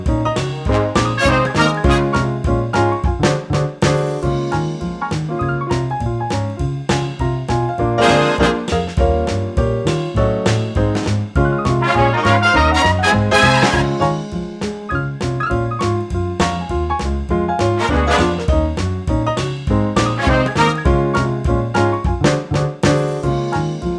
no Backing Vocals Oldies (Female) 3:00 Buy £1.50